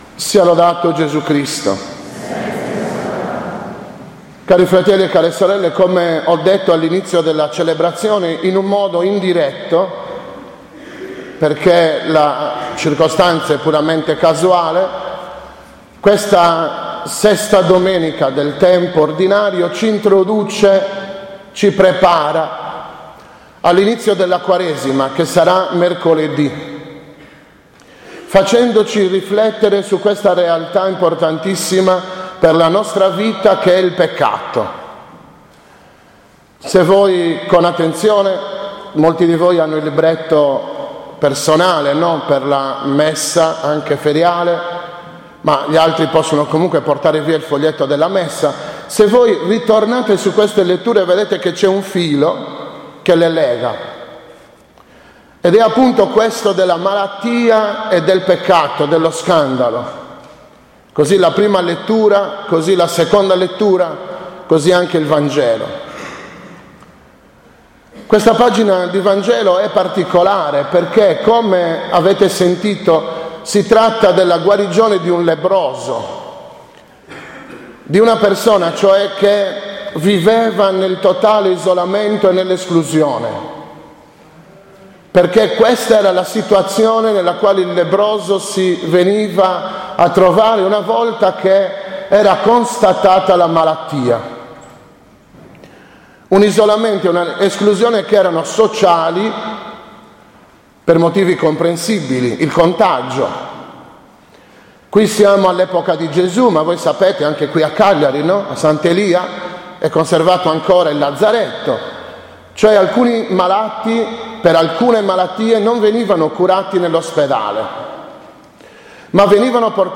15.02.2015 – OMELIA DELLA VI DOMENICA DEL TEMPO ORDINARIO